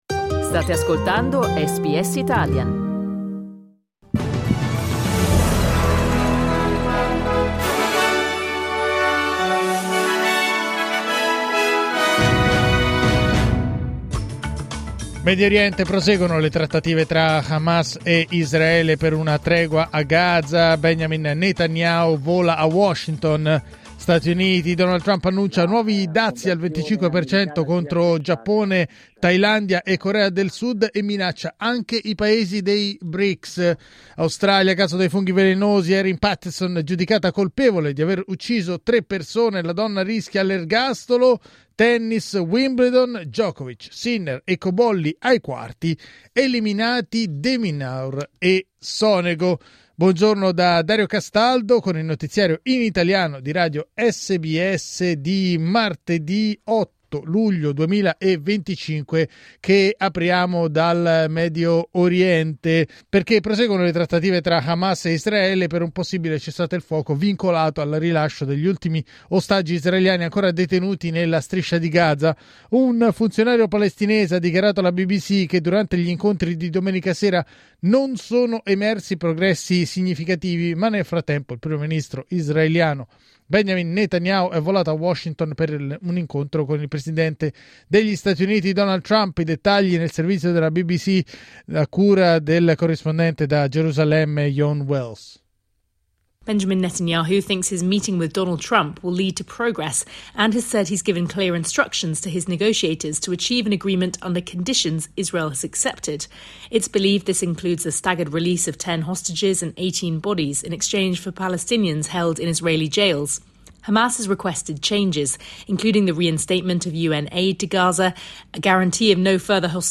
Giornale radio martedì 8 luglio 2025
Il notiziario di SBS in italiano.